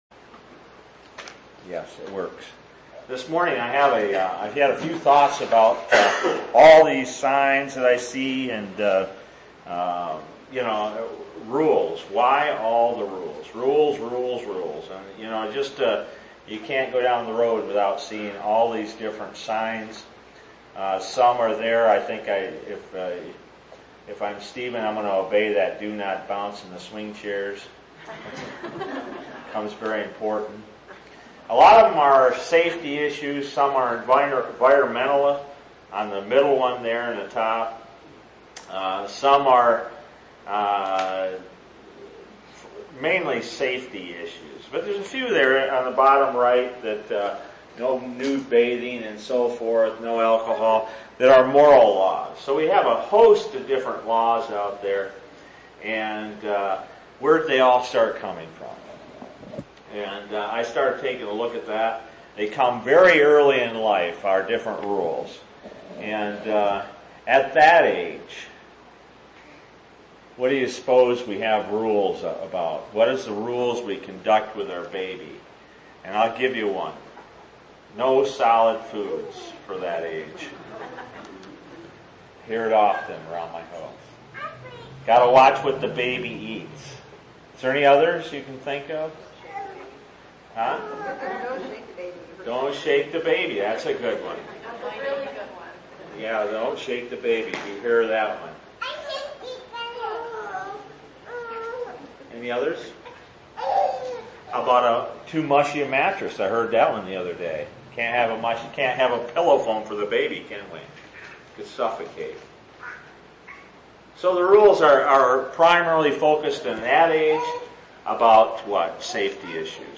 6/16/2013 Location: Bradley Local Event